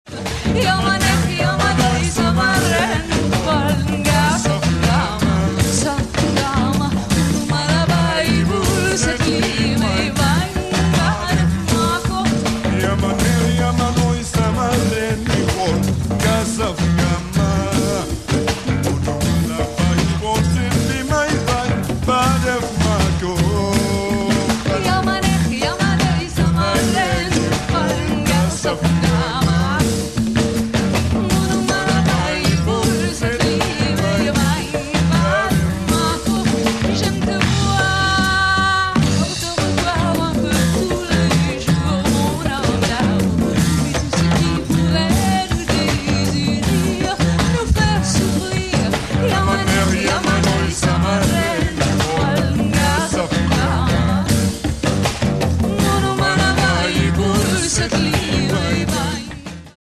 percussionist
vocalist
guitar
bass
keyboards